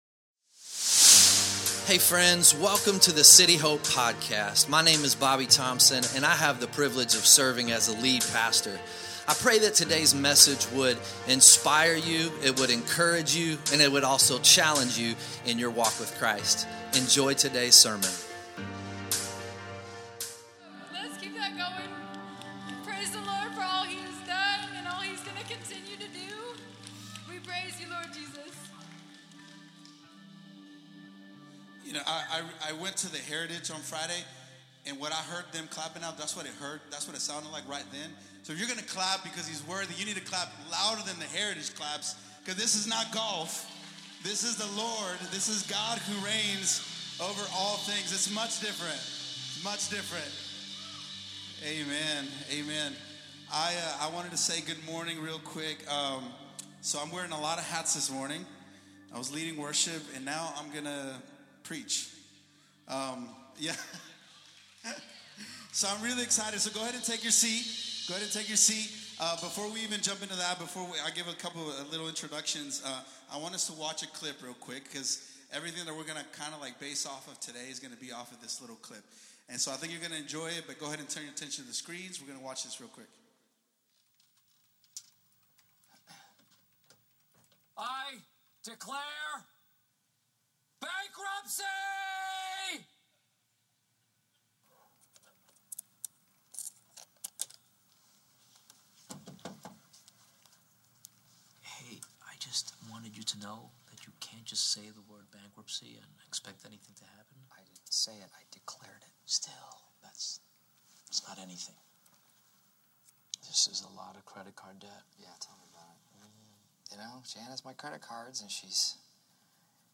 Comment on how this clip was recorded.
2026 Sunday Morning At the end of the day